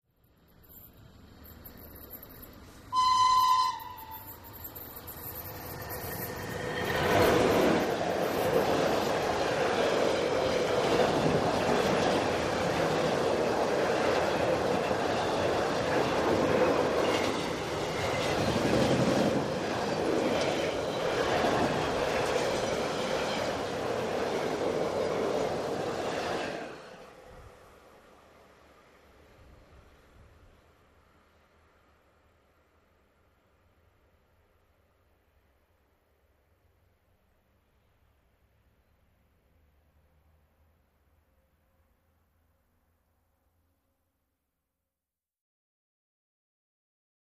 Electric Train ( European ), By Very Fast, Cu with Horn, Rail Noise.